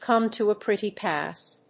アクセントcòme to a prétty [fíne] páss